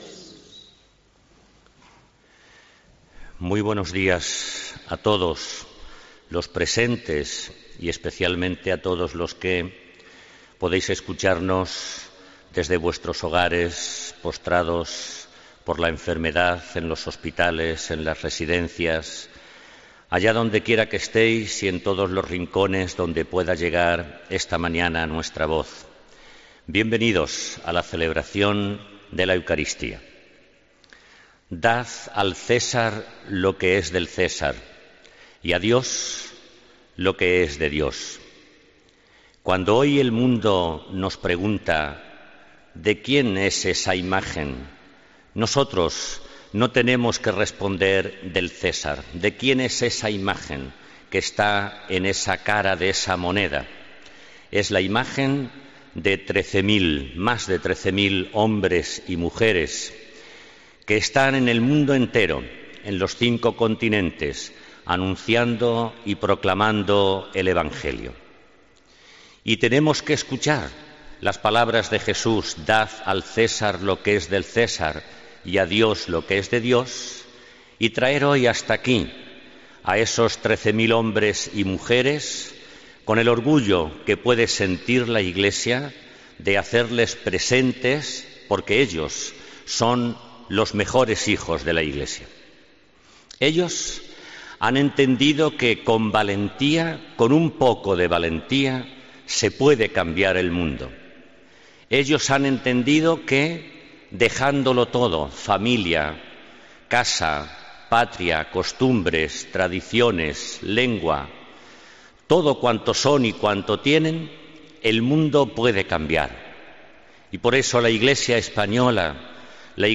Homilía del 22 de octubre de 2017